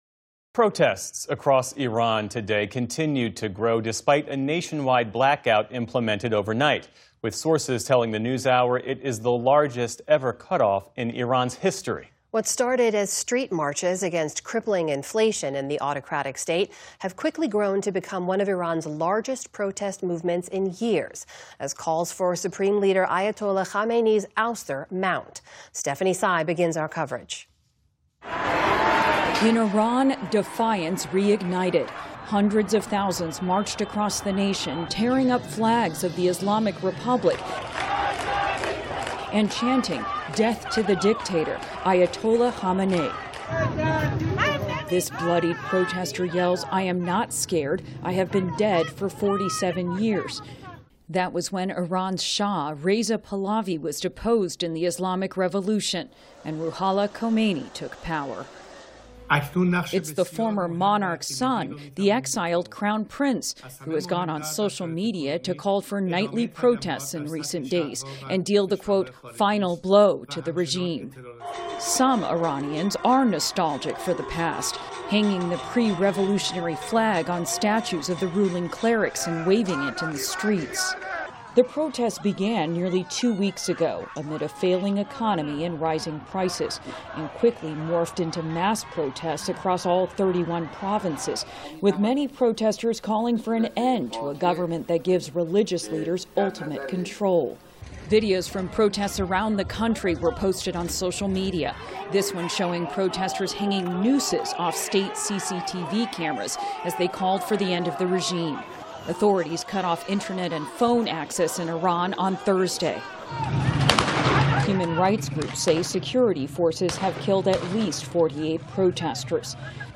PBS News Hour - World